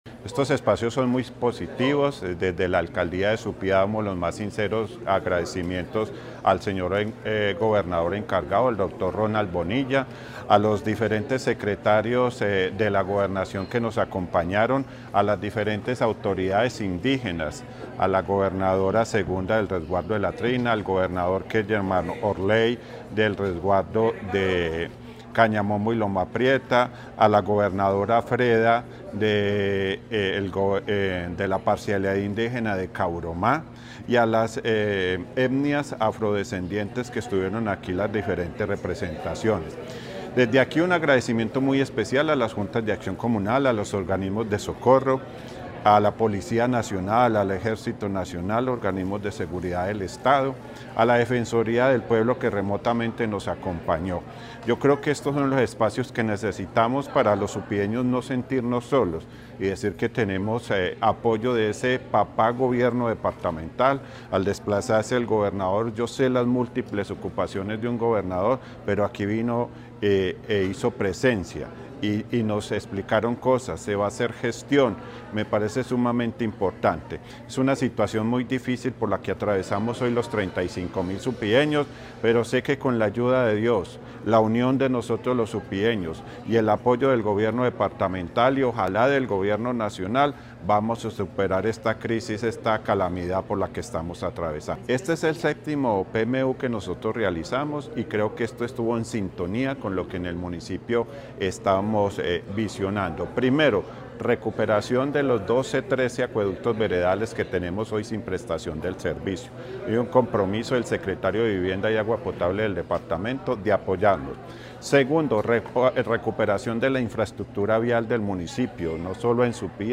Héctor Mauricio Torres Álvarez, alcalde de Supía